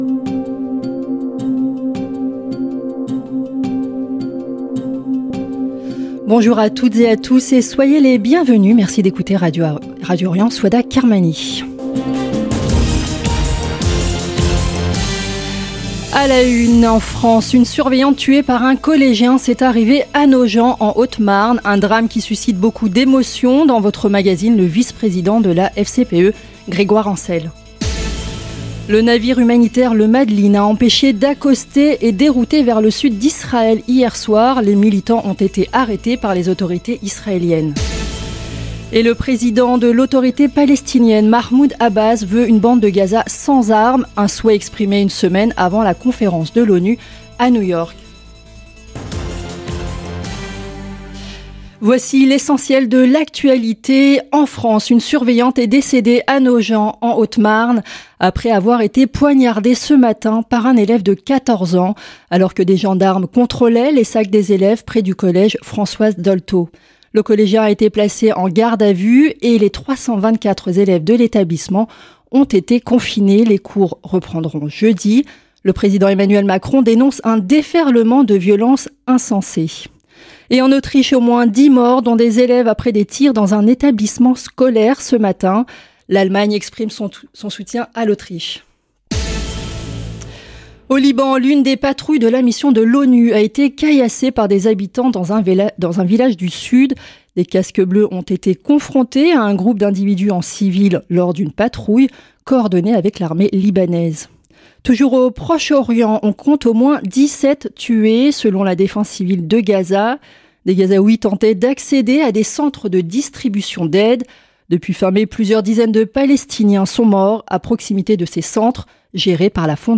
Magazine de l'information de 17 H00 du mardi 10 juin 2025